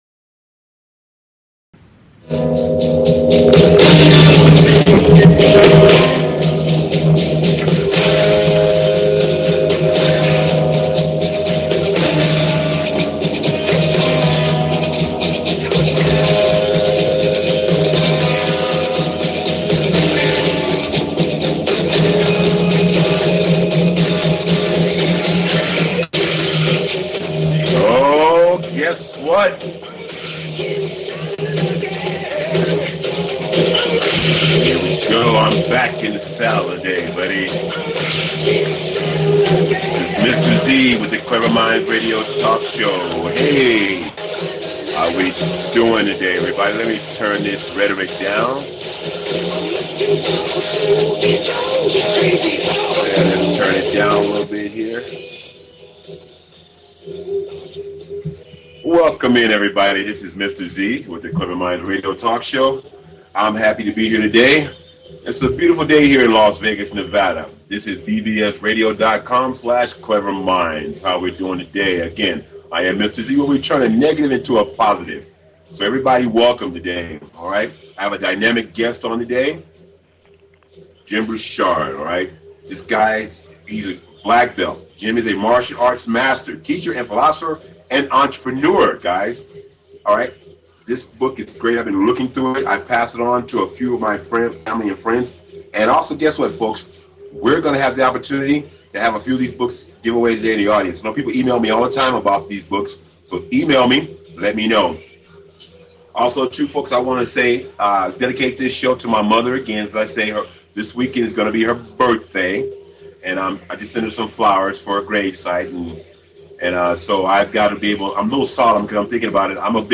Talk Show Episode, Audio Podcast, Cleverminds and Courtesy of BBS Radio on , show guests , about , categorized as